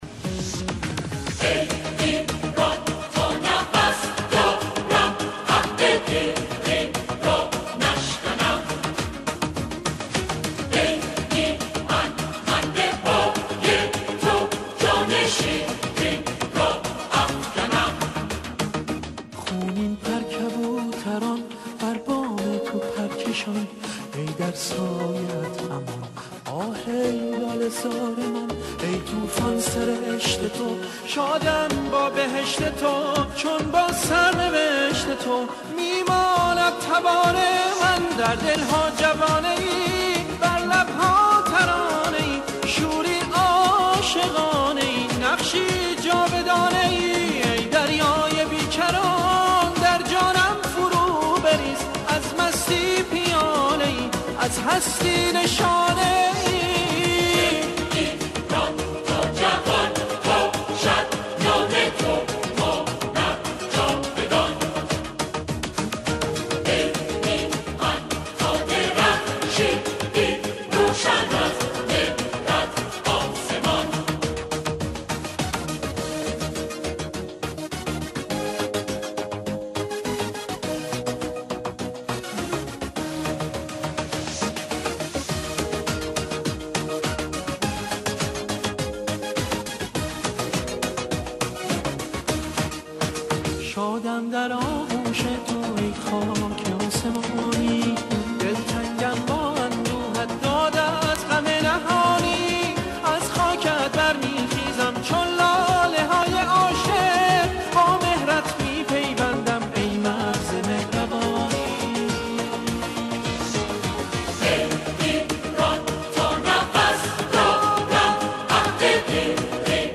آهنگساز و خواننده